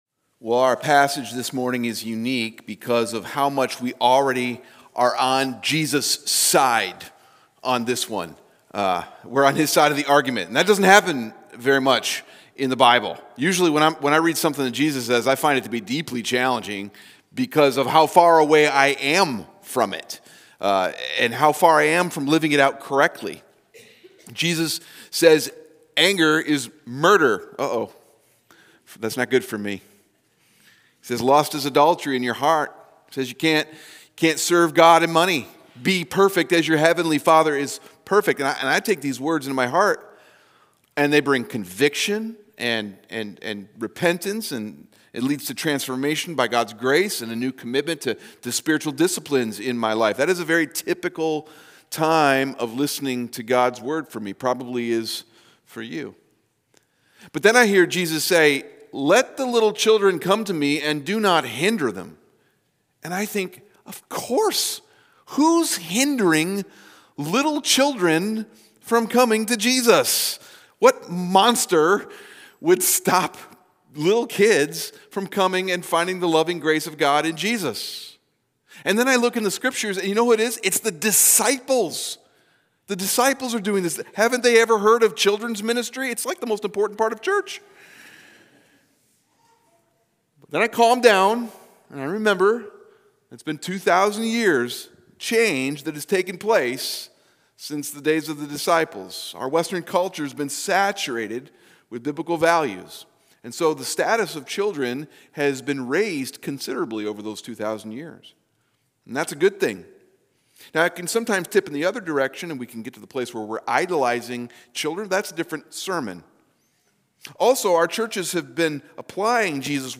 That’s a different sermon.